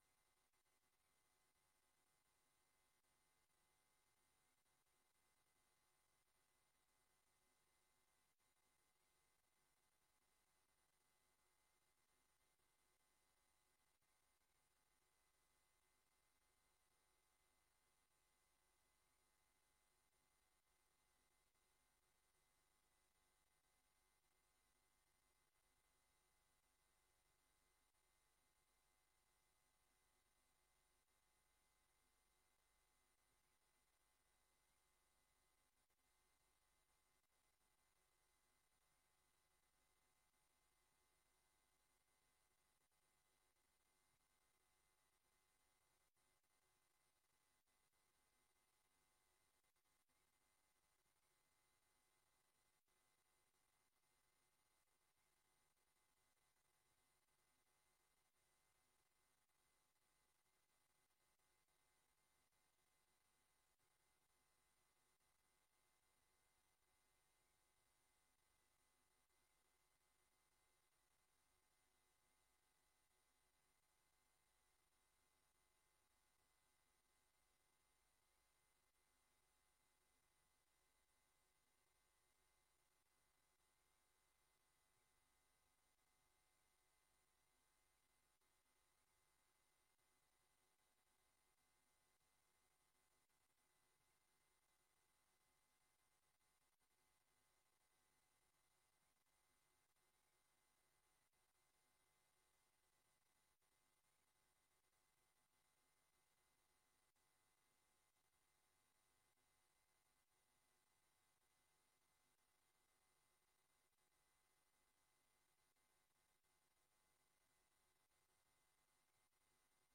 Commissie Samenlevingszaken 30 oktober 2025 19:30:00, Gemeente Noordoostpolder
Download de volledige audio van deze vergadering